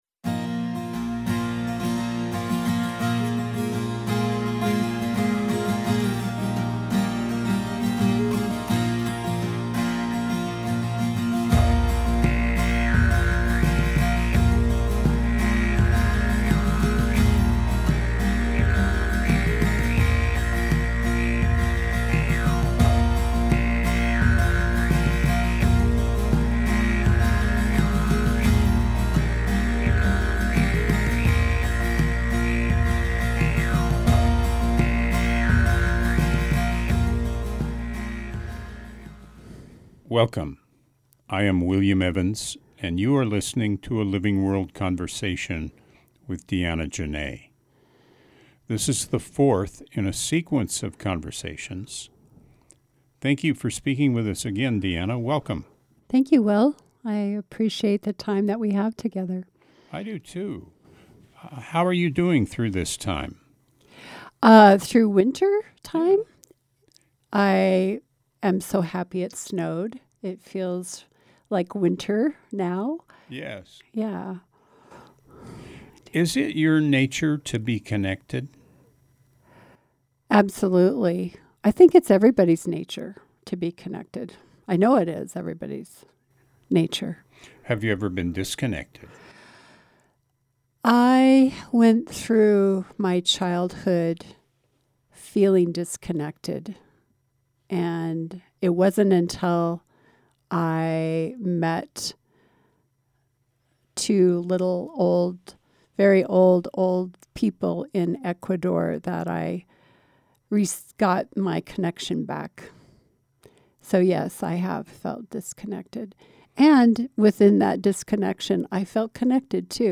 Shifting Gears features conversations with people making life-sustaining choices.